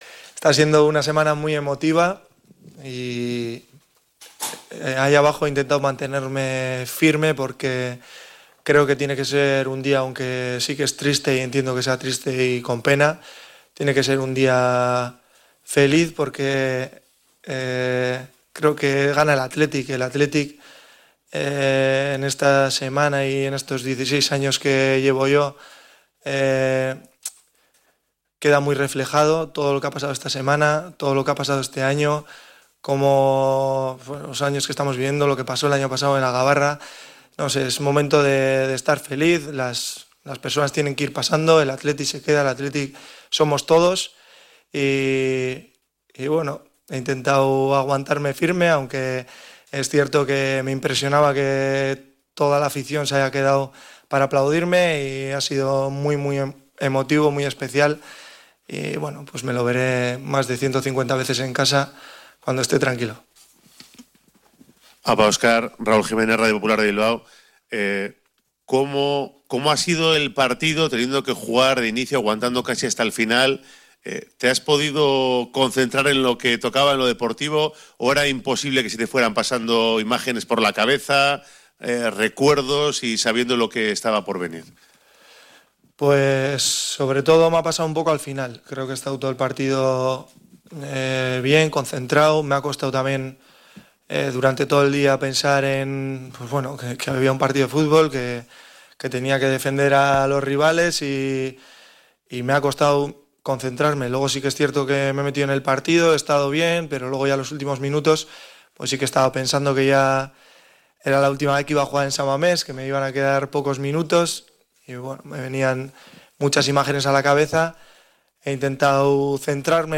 Repasa la última rueda de prensa del "Gudari" de Marcos
DM-ULTIMA-RUEDA-DE-PRENSA.mp3